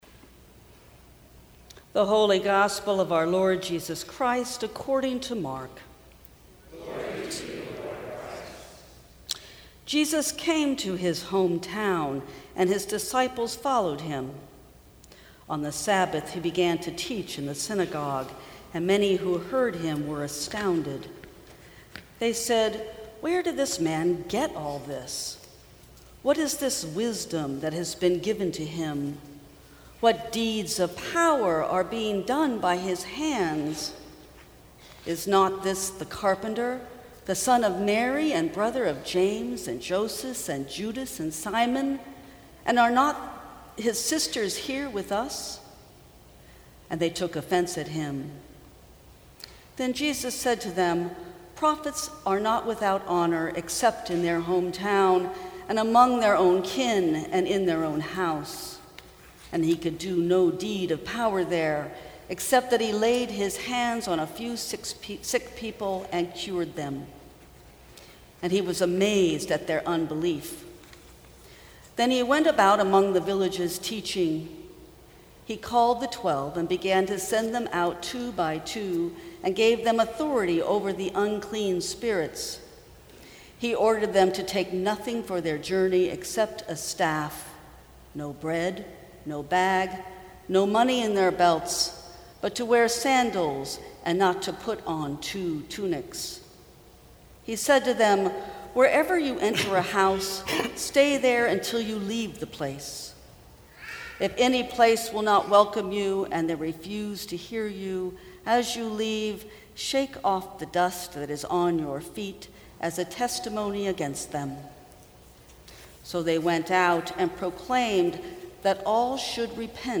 Sermons from St. Cross Episcopal Church Straight Out of Nazareth Jul 12 2018 | 00:12:04 1x 00:00 / 00:12:04 Subscribe Share Apple Podcasts Spotify Overcast RSS Feed Share Link Embed